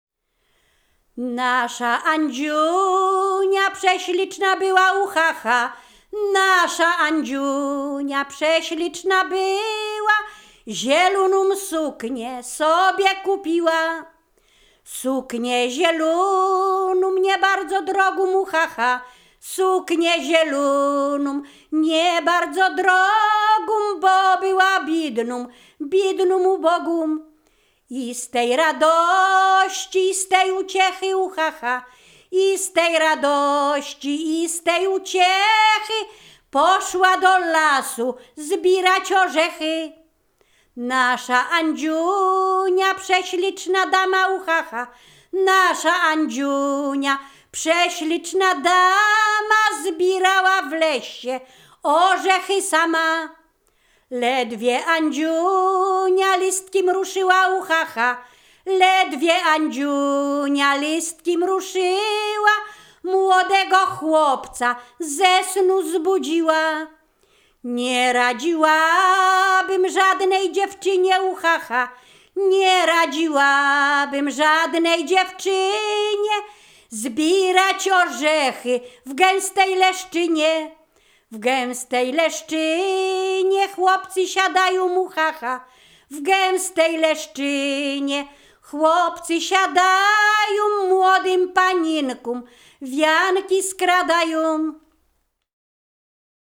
Ziemia Radomska
liryczne miłosne pieśni piękne żartobliwe